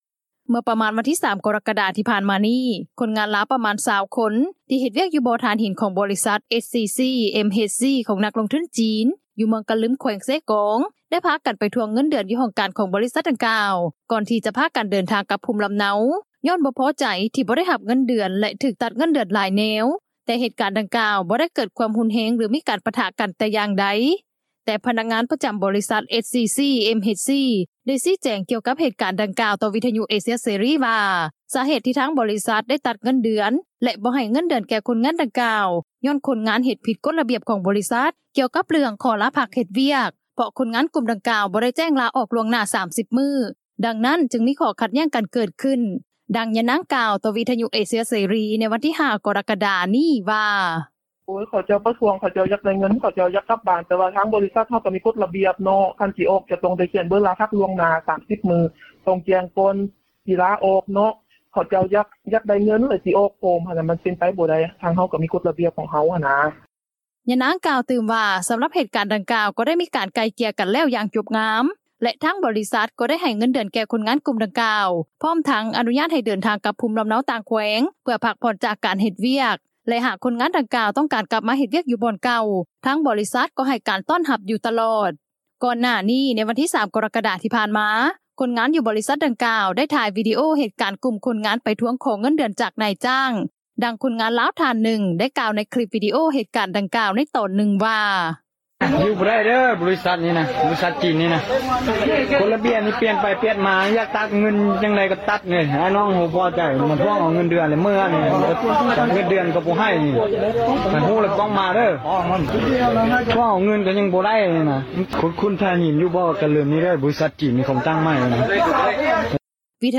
ດັ່ງຄົນງານລາວ ທ່ານນຶ່ງ ໄດ້ກ່າວໃນຄລິບວີດີໂອ ເຫດການດັ່ງກ່າວ ໃນຕອນນຶ່ງວ່າ:
ດັ່ງເຈົ້າໜ້າທີ່ ນາງນຶ່ງ ກ່າວວ່າ: